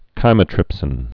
(kīmə-trĭpsĭn)